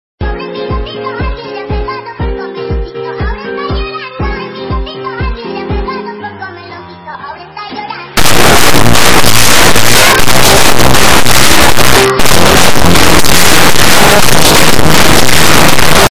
ay mi gatito bass booted Meme Sound Effect